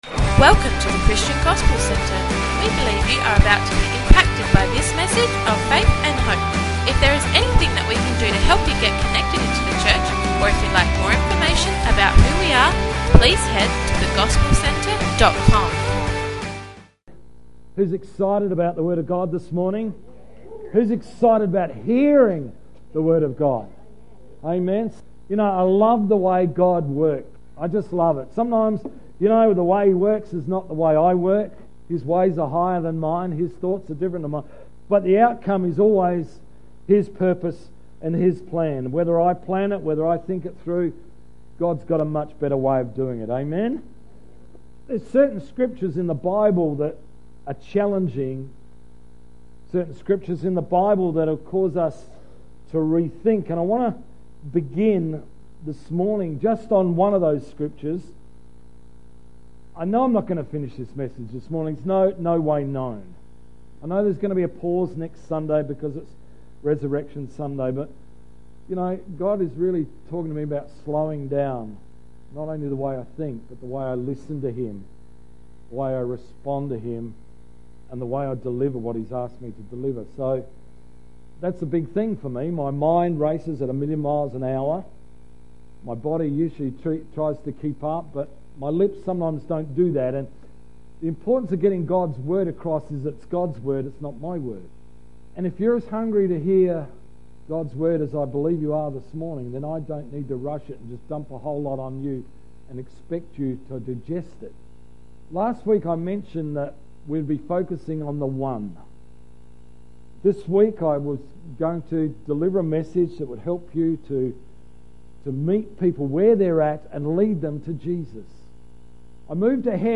20th March 2016 – Morning Service